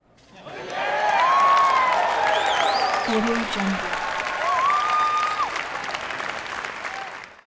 دانلود افکت صوتی تشویق و سوت و کف کوتاه تماشاچیان فیلم در سالن سینما
Crowds Theater CheerTheatre500 PeopleEruptYeahWooWhistles royalty free audio track is a great option for any project that requires human sounds and other aspects such as a cheer, theatre and people.
Sample rate 16-Bit Stereo, 44.1 kHz